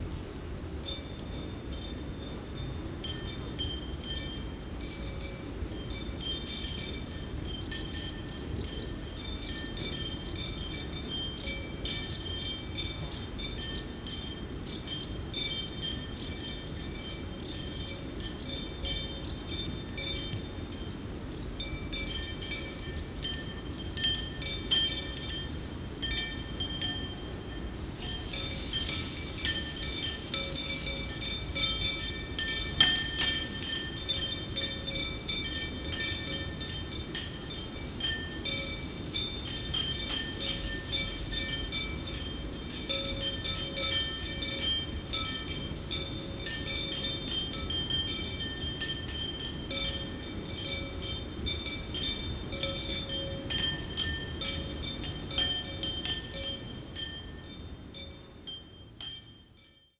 In that journey which starts with the ringing bells, we wander through the beautiful areas full of other great performances and at the end we reach the final destination to listen to the minimalistic solo with the simple accompaniment.
The sounds I gathered during my one month travel in Georgia.
„Overture“ that opens the album begins with the recording of metallic objects that twinkle like bells.